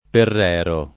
[ perr $ ro ]